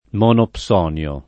vai all'elenco alfabetico delle voci ingrandisci il carattere 100% rimpicciolisci il carattere stampa invia tramite posta elettronica codividi su Facebook monopsonio [ m q nop S0 n L o ] s. m. (econ.); pl. -ni (raro, alla lat., -nii )